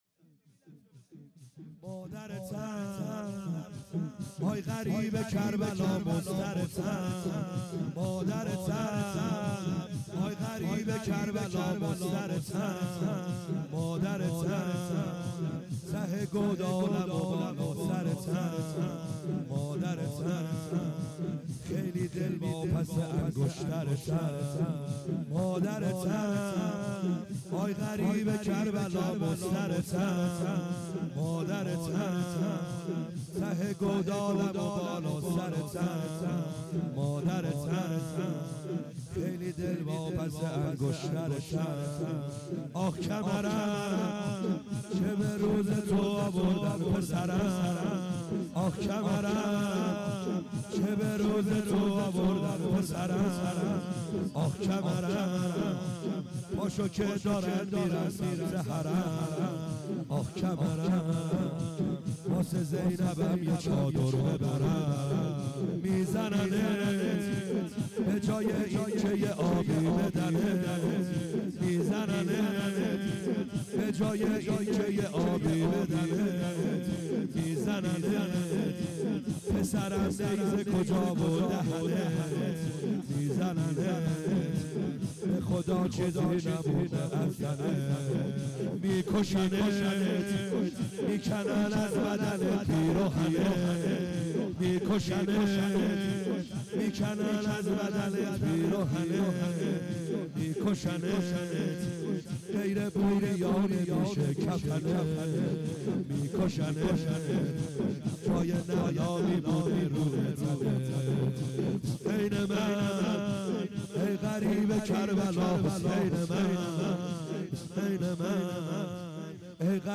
خیمه گاه - بیرق معظم محبین حضرت صاحب الزمان(عج) - لطمه زنی | مادرتم
شب دهم محرم اشتراک برای ارسال نظر وارد شوید و یا ثبت نام کنید .